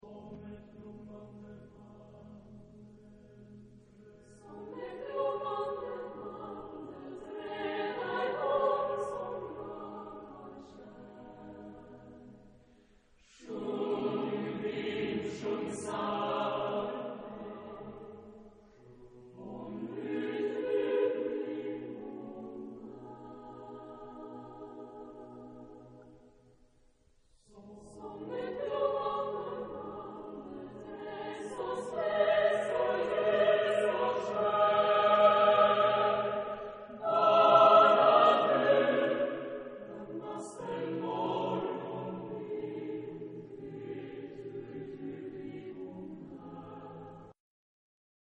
SATB (4 voix mixtes) ; Partition complète.
Chanson poétique.
lyrique ; mélodieux ; tendre ; chaleureux
Tonalité : mi majeur